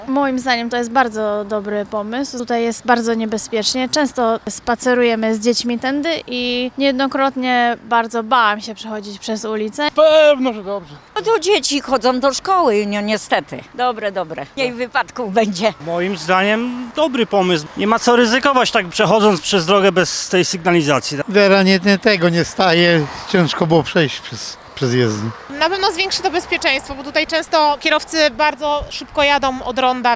Mieszkańcy są zadowoleni z nowej sygnalizacji: